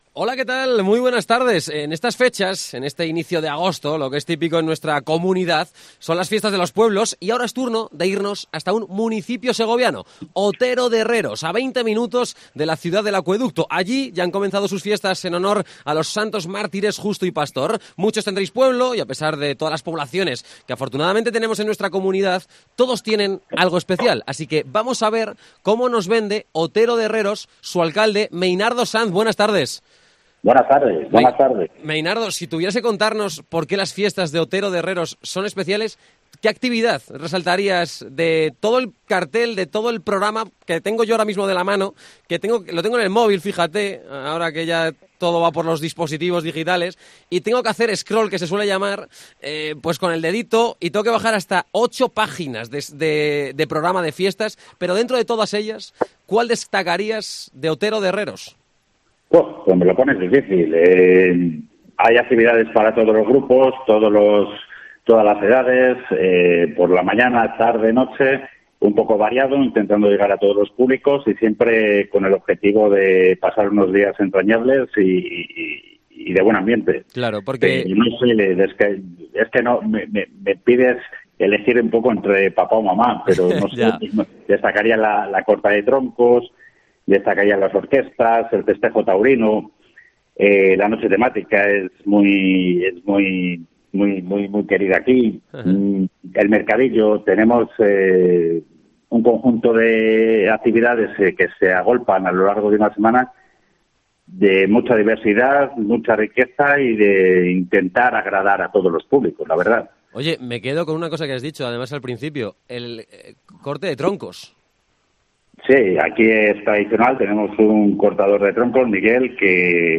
AUDIO: Entrevista a Meinardo Sanz, Alcalde de Otero de Herreos.